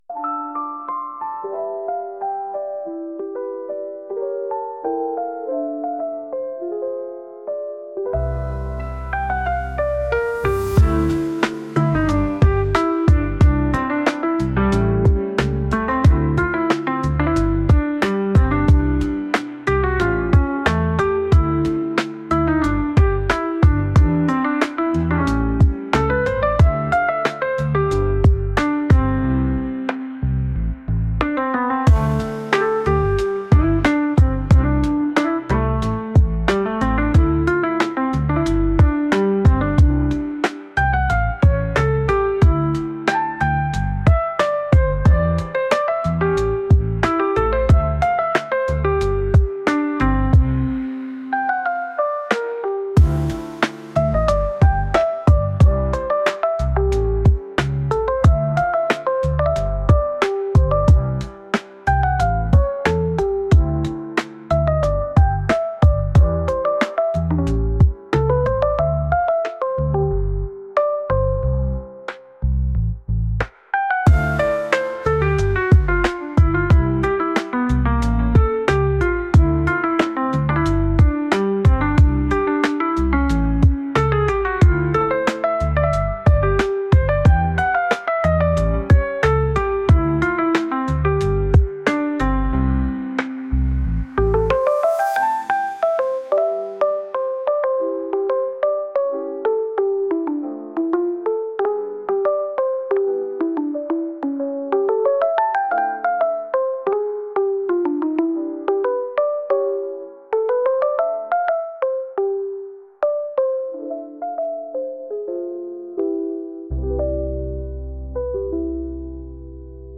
ゆったりしたピアノ曲です。